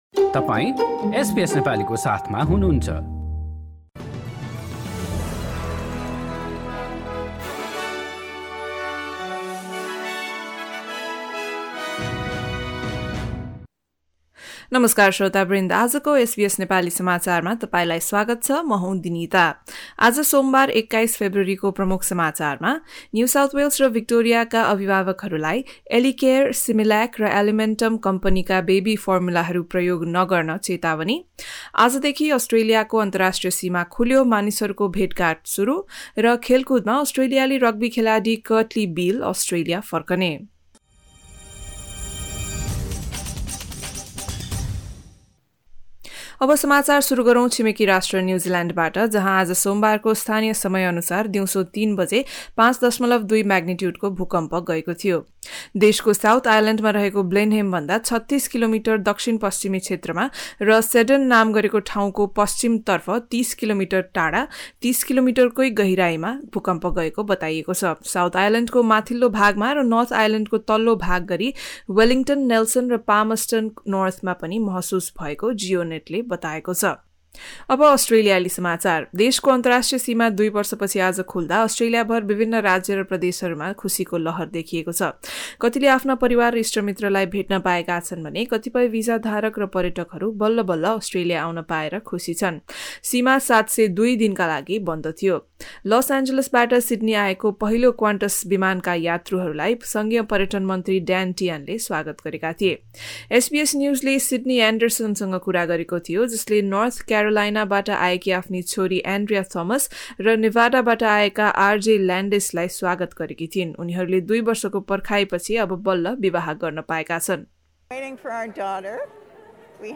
In this bulletin: emotional reunions as international travellers return to Australia, New South Wales and Victoria health authorities warn parents to stop feeding their babies three brands of formula amongst fear of bacteria contamination and Australian Indigenous rugby union star Kurtley Beale to return to Australia in a bid to contest a record-equalling fourth Rugby World Cup.